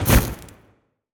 fireball_impact_burn_02.wav